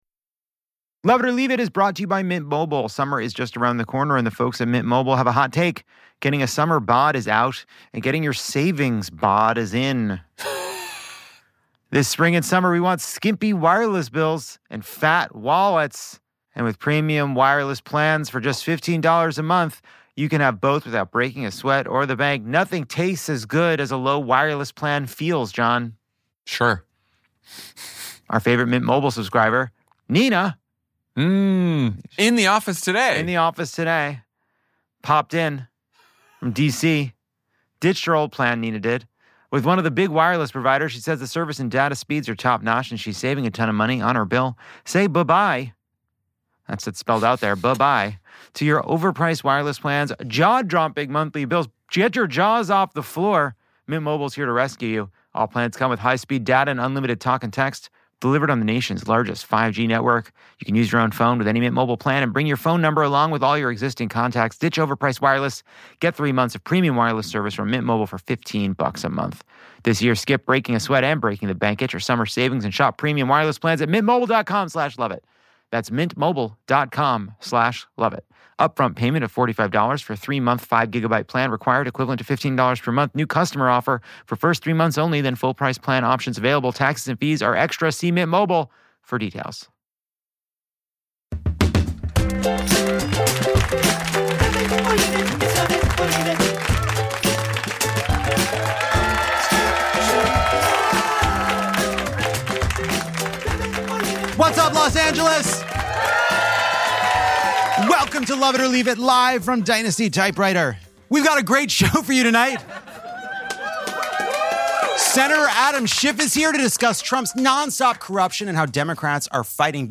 It’s another 10/10 week in America, as Donald Trump plans a taxpayer-funded birthday spectacle, RFK Jr. and Dr. Oz team up for an ostrich heist, and we all reluctantly continue to stand with Harvard University. Senator Adam Schiff stops by to break down Trump’s corruption spree.